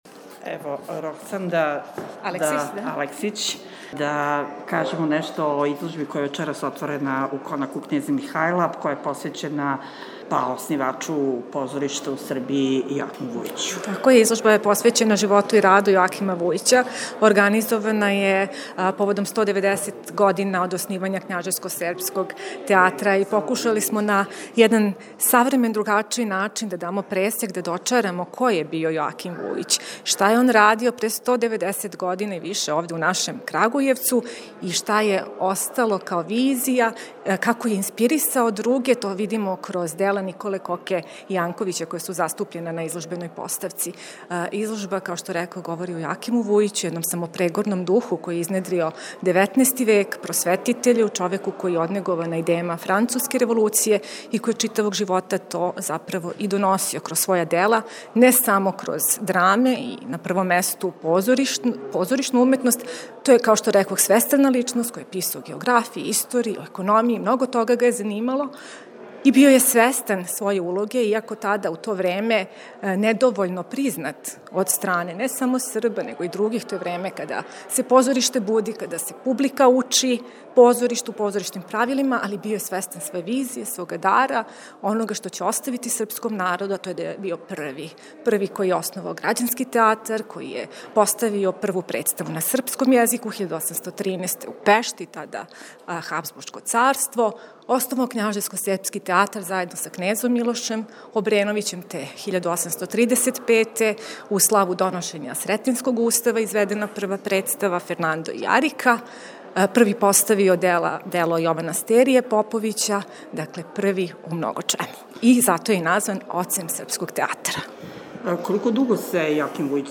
Истог дана свечано је отворена изложба Аферим, Аћиме! посвећена Јоакиму Вујићу, оснивачу Књажевско-српског театра. Изложба је постављена у галерији Конака кнеза Михаила и реализована је у сарадњи са Народним музејом Шумадије. Ми смо о томе разговарали са једном од ауторки историчарком